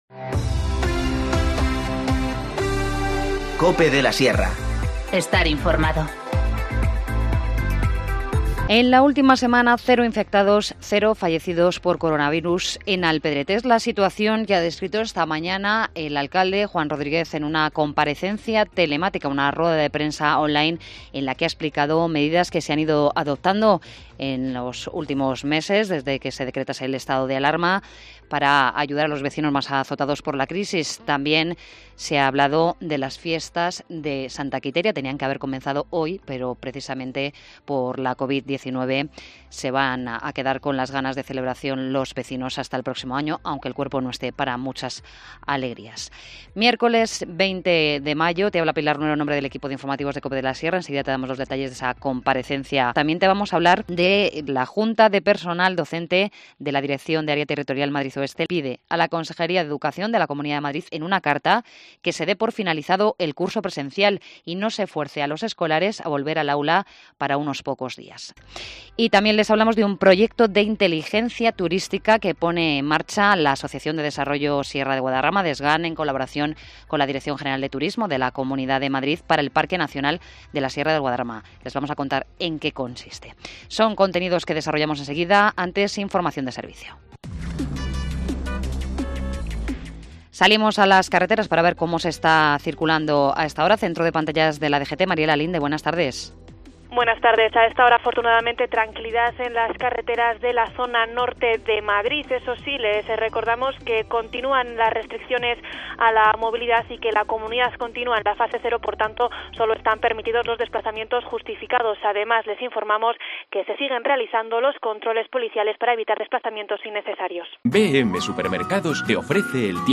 Informativo Mediodía 20 mayo 14:20h